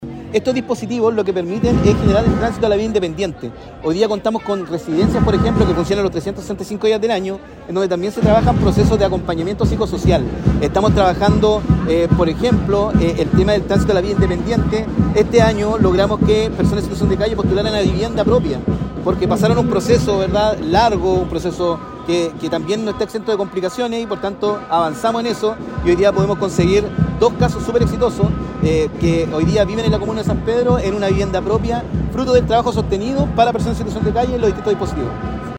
Con respecto a las acciones tomadas por el gobierno para reducir la población en situación de calle, el seremi explicó que se está trabajando para que las personas postulen a viviendas propias.